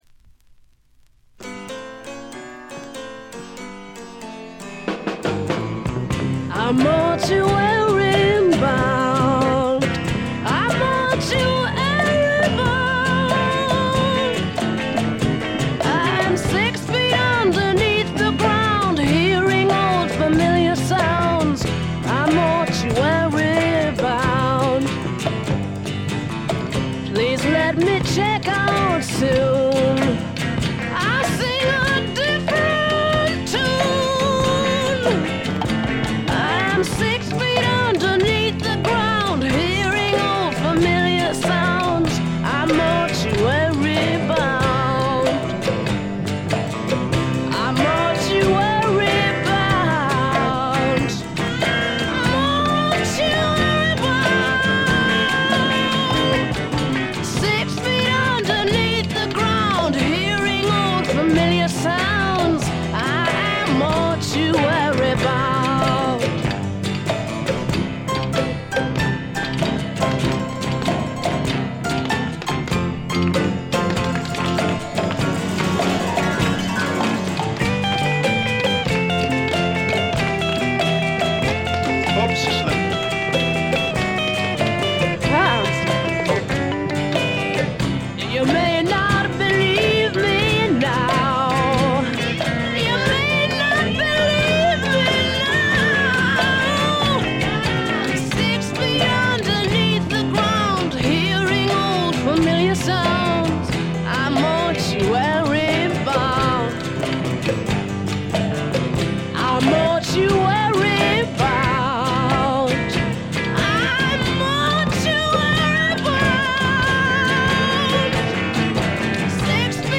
カナダ出身の女性シンガーソングライターが残したサイケ／アシッド・フォークの大傑作です。
試聴曲は現品からの取り込み音源です。